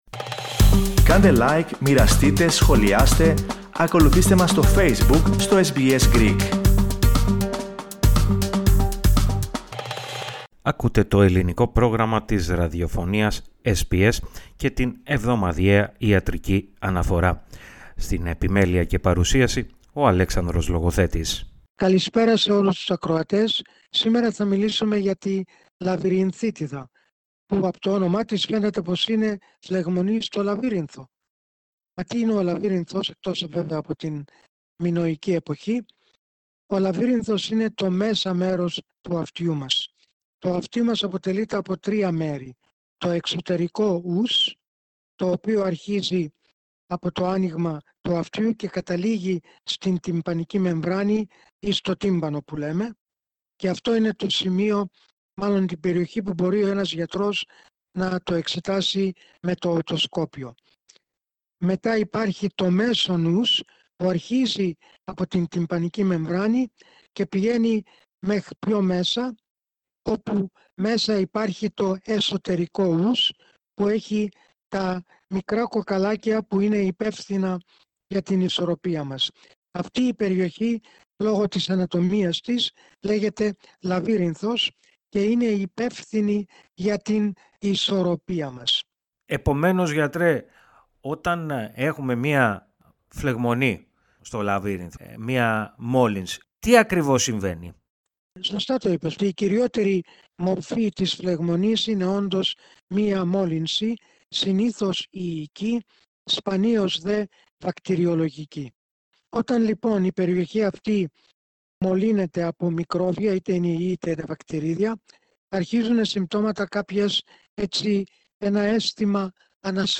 Ο γενικός ιατρός